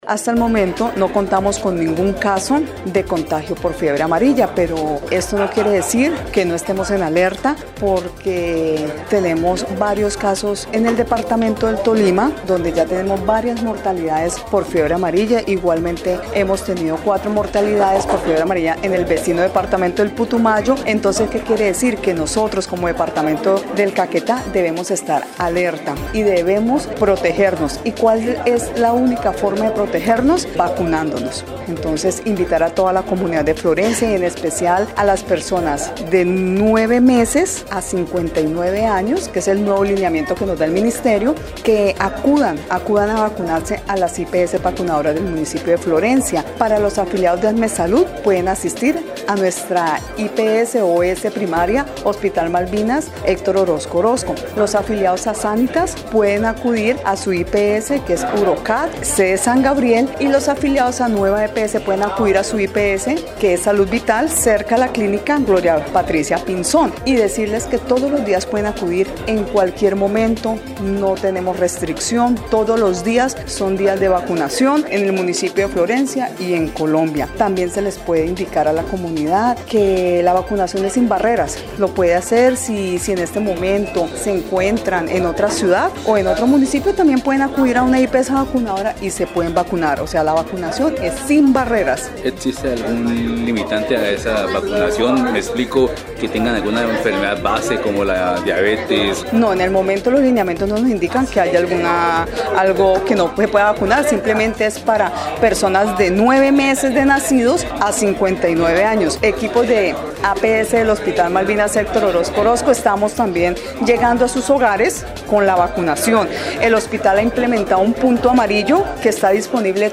Sandra Liliana Vallejo, secretaria de salud municipal, explicó que, departamentos vecinos, registran muertes por esta patología, por ello se avanza en jornadas de vacunación diarias en las diferentes IPS vacunadoras.